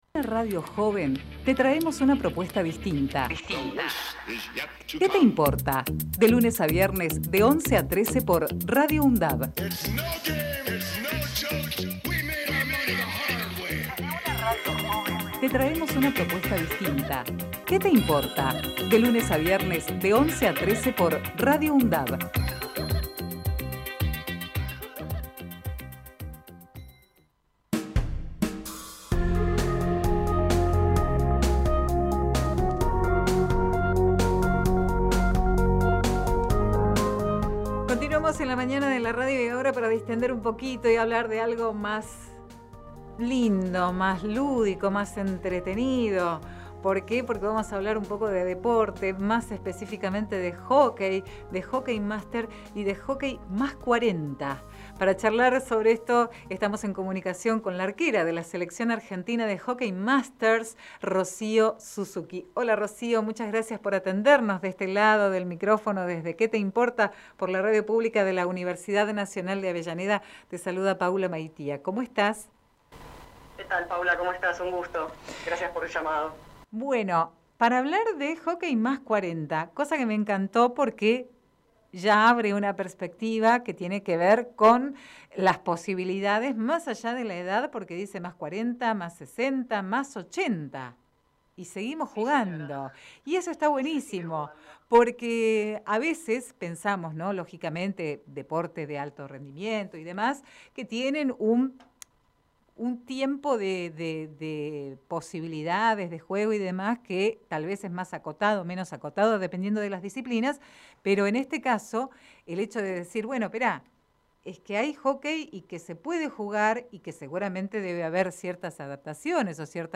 Compartimos la entrevista realizada en "Que te importa?!"